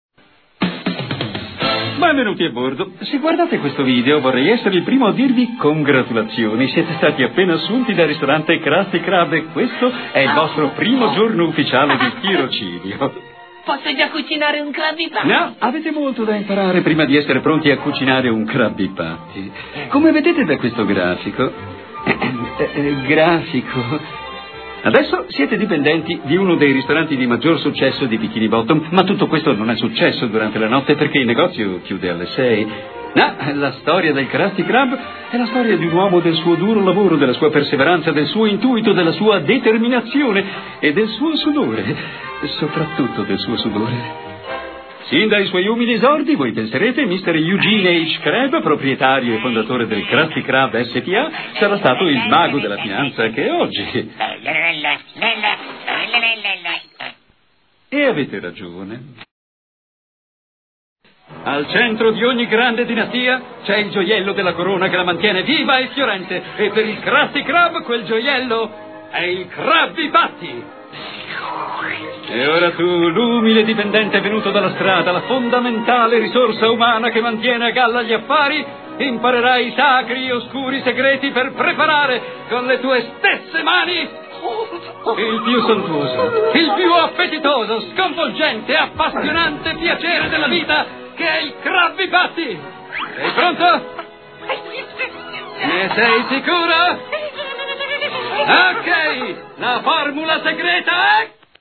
nel cartone animato "SpongeBob", in cui doppia Gary.